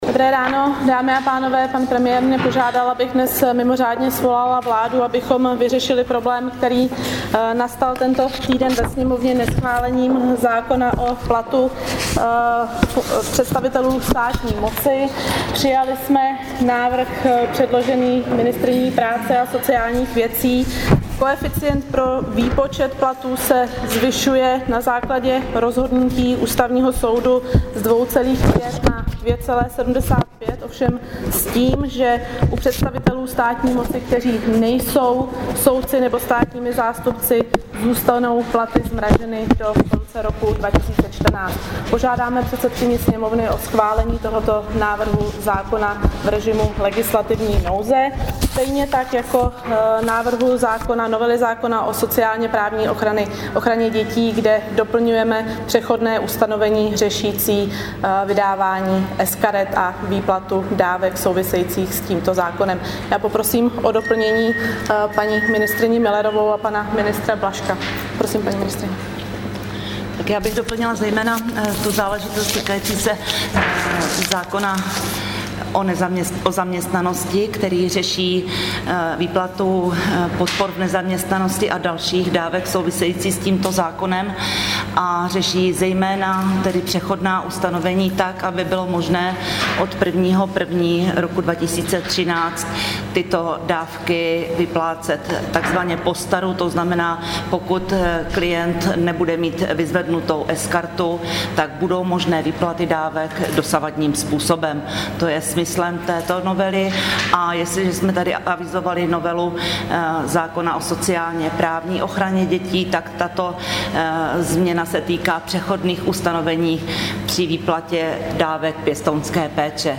Tisková konference po mimořádném jednání vlády, 14. prosince 2012